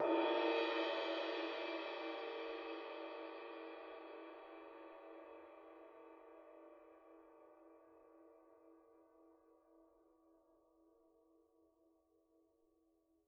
susCymb1-hit_mp_rr2.wav